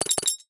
NOTIFICATION_Metal_04_mono.wav